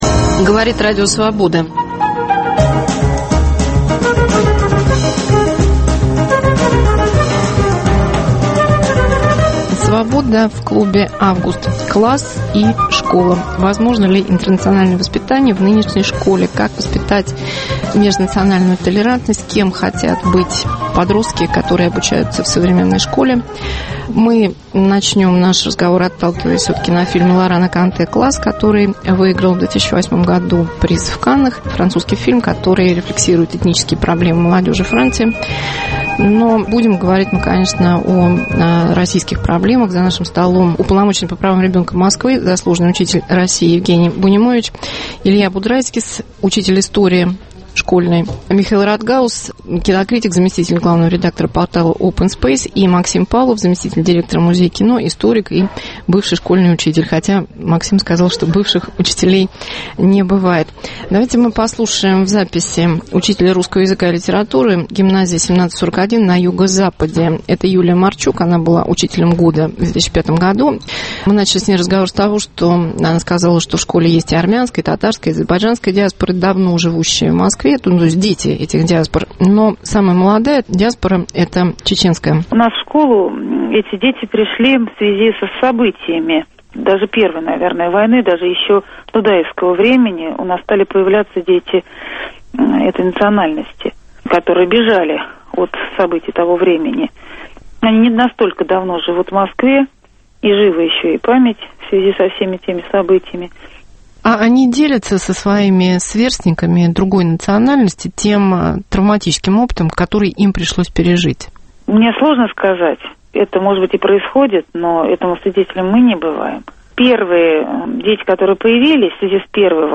Свобода в клубе "Август". "Класс" и "Школа": межнациональные отношения в зеркале образования и кинематографа.